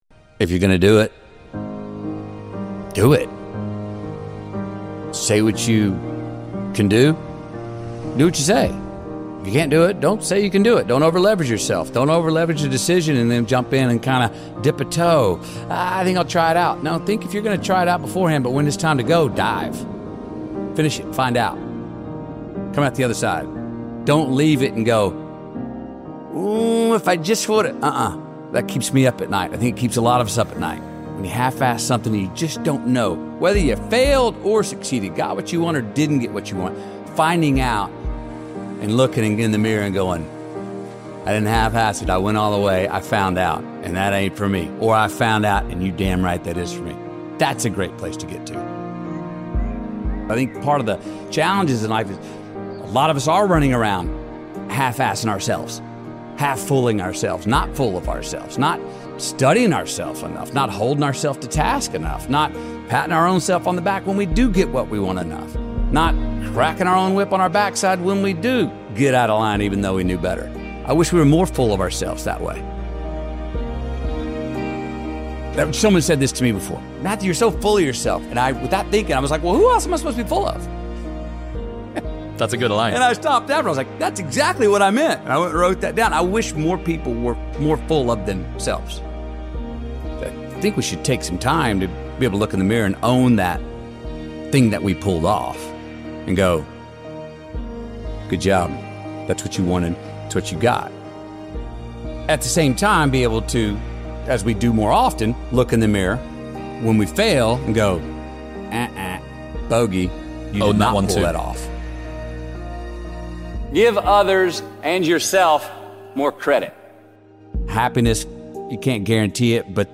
Your morning determines your day! One of the BEST MORNING MOTIVATIONAL VIDEOS featuring speeches by Matthew McConaughey!